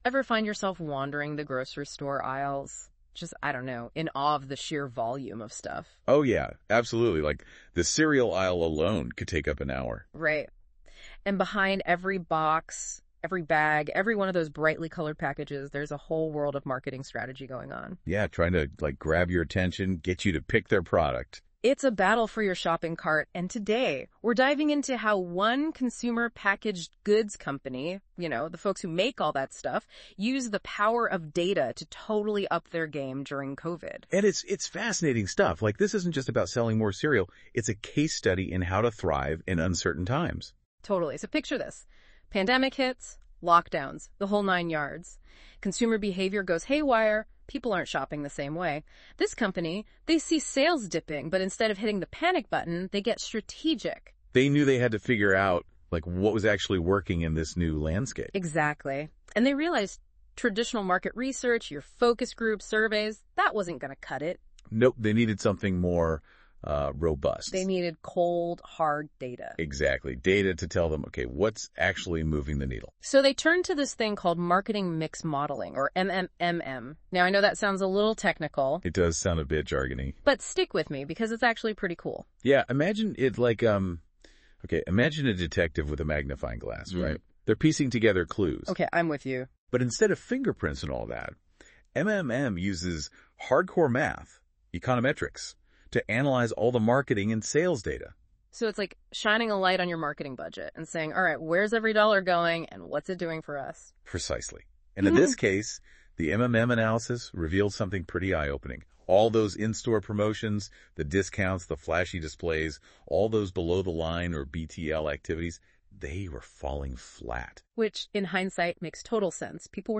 Note : This Podcast is generated through Notebook LM.